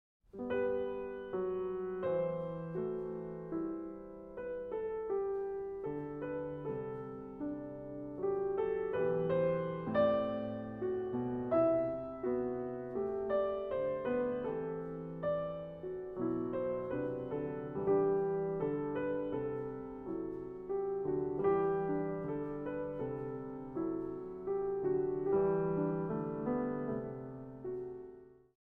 Works for piano